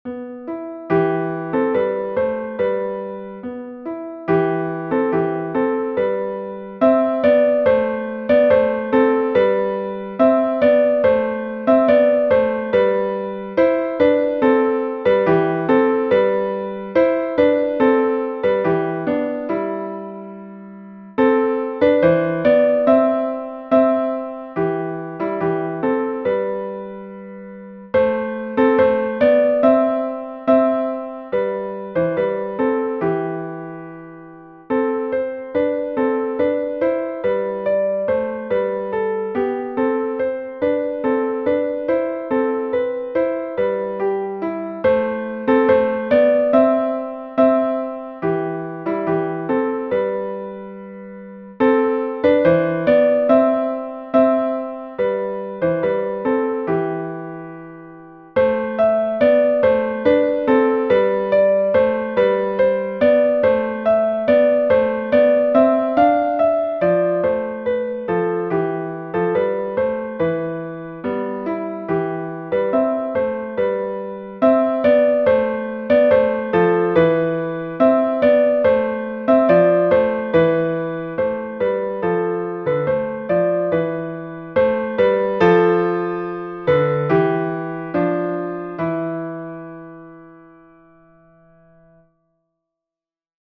easy and engaging piano solos
Instructional, Medieval and Renaissance